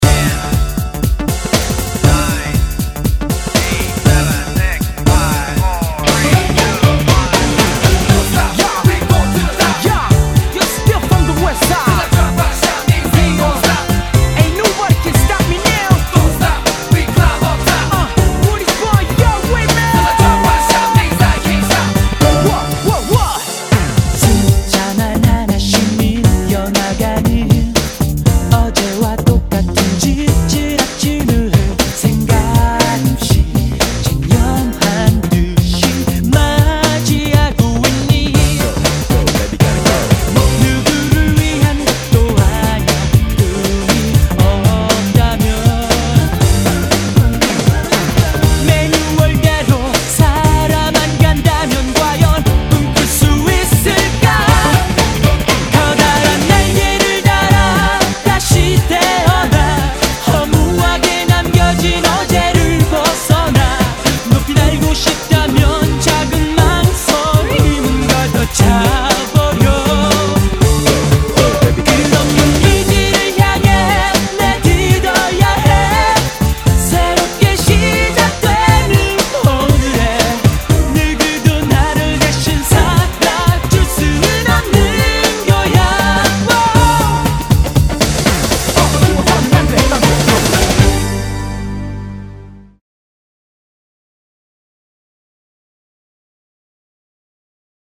BPM119--1
Audio QualityPerfect (High Quality)